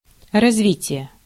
Ääntäminen
IPA: /rɐzˈvʲitʲɪjə/